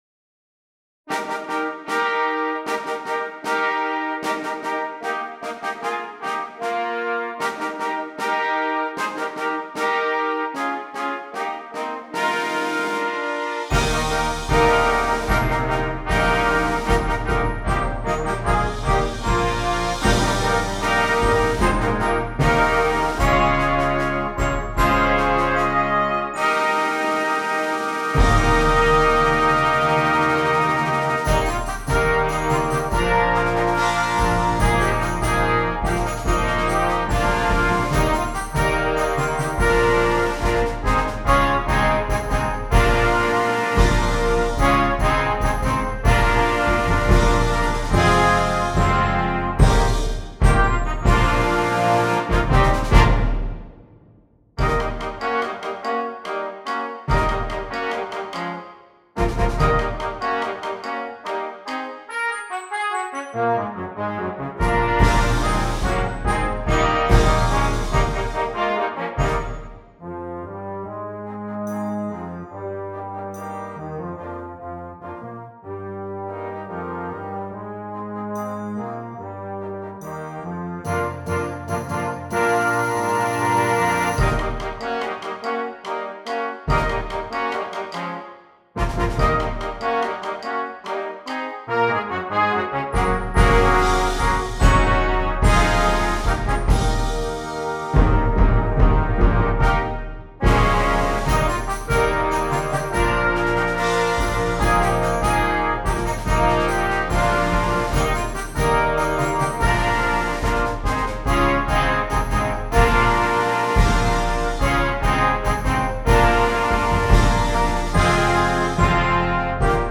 Brass Choir
a short fanfare/overture